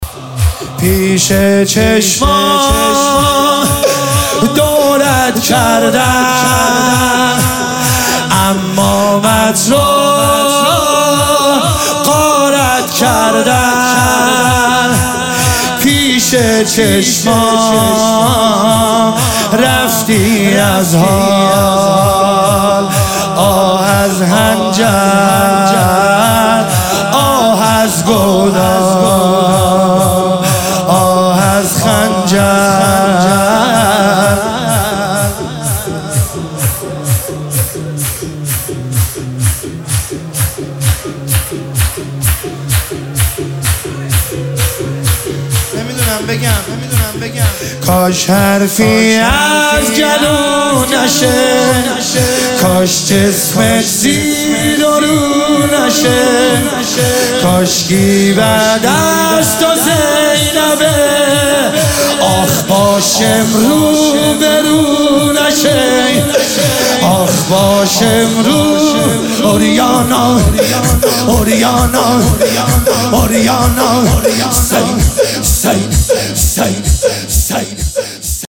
مداحی شور شب پنجم محرم